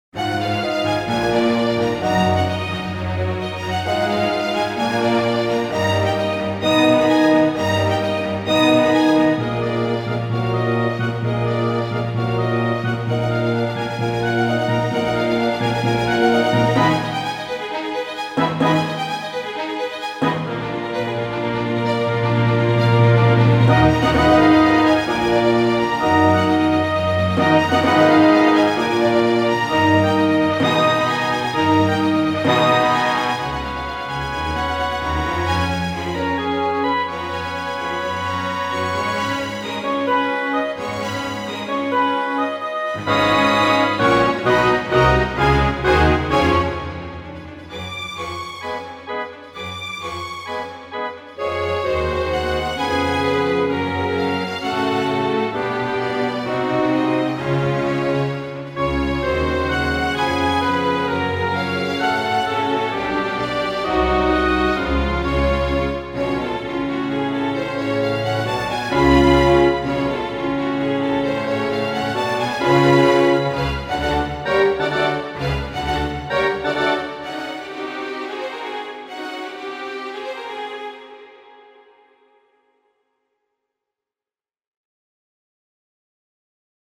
• サウンドプログラマがDTM(打ち込み)で制作した高品質なクラシックオーケストラmp3を公開しています。
♪サウンドプログラマ制作の高品質クラシックオーケストラ。